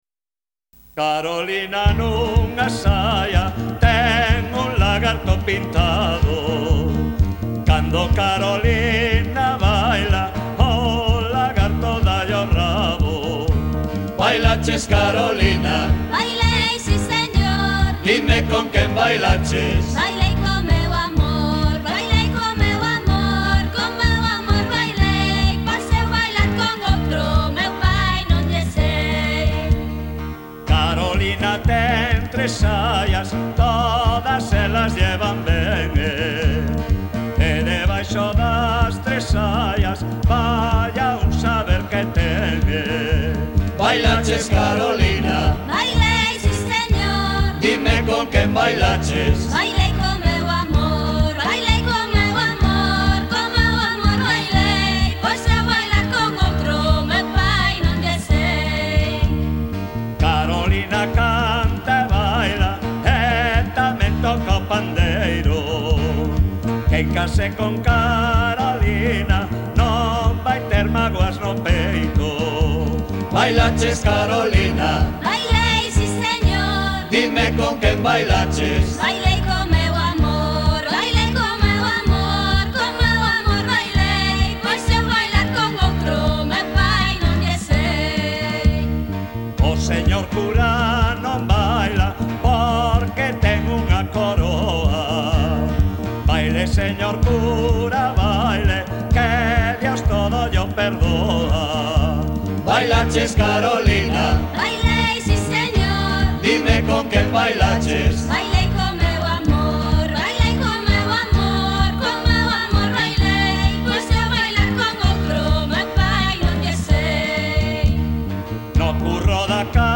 Letra: Popular